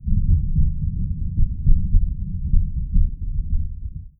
THUNDER_Rumble_01_mono.wav